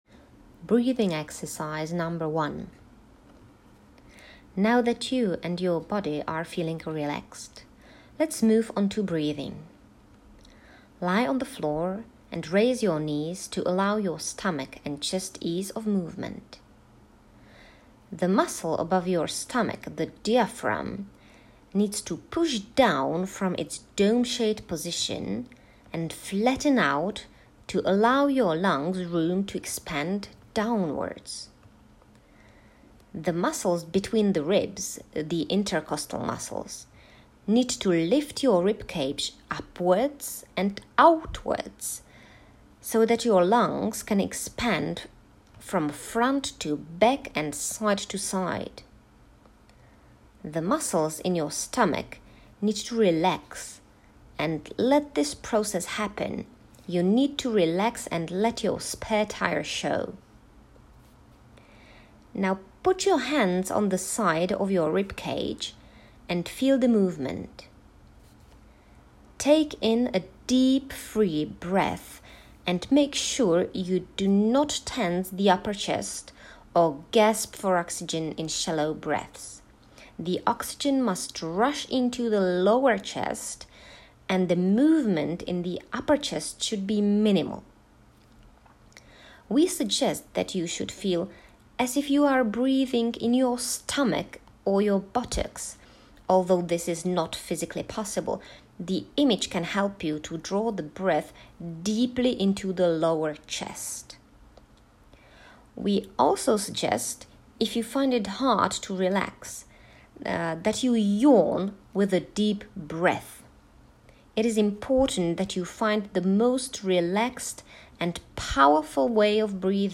breathing_exercise_1.m4a